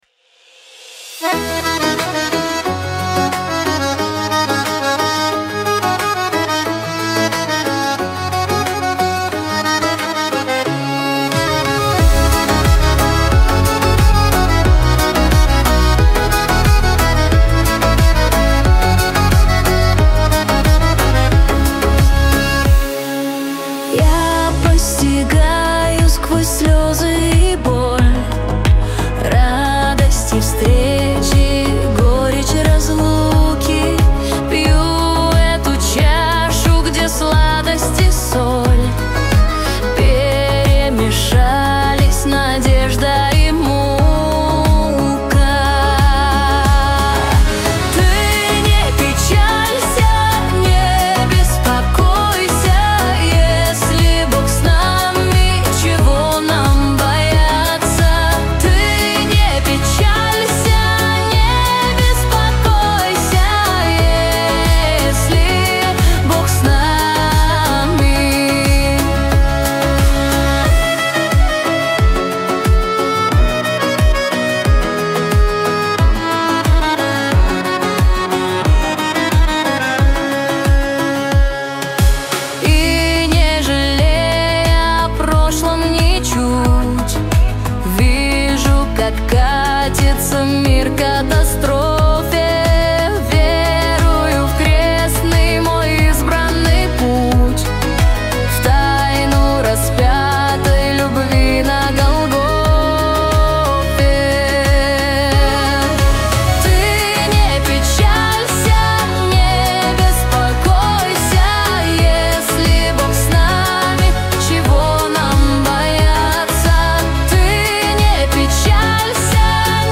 песня ai
204 просмотра 725 прослушиваний 84 скачивания BPM: 90